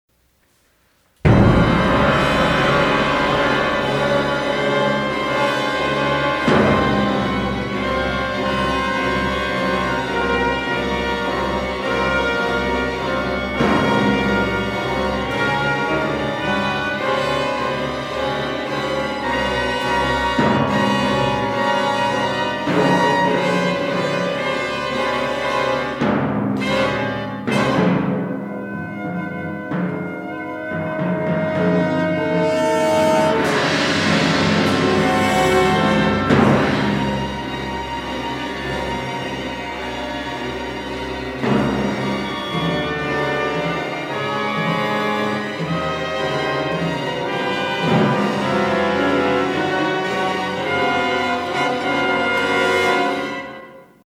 |orchestra|